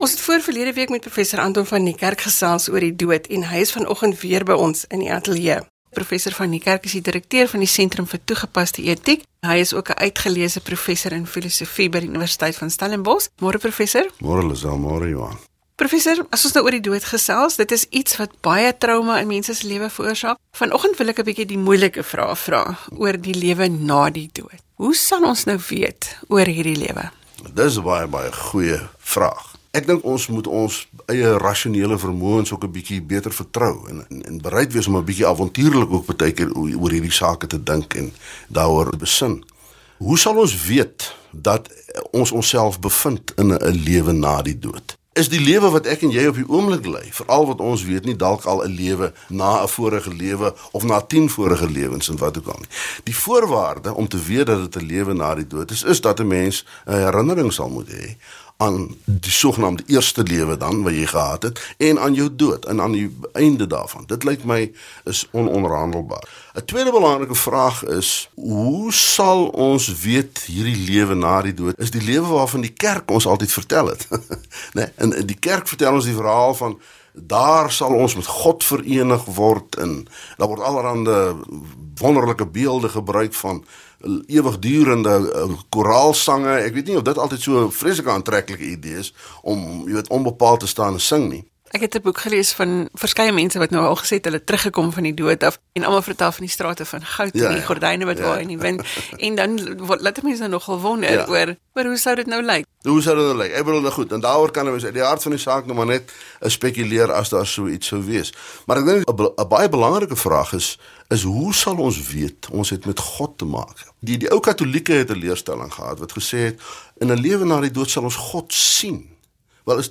Na ons eerste onderhoud het ons nog net aan een aspek geraak en ek het toe gevra of hy dalk oor die ander twee vra ‘n opvolg onderhoud sal doen.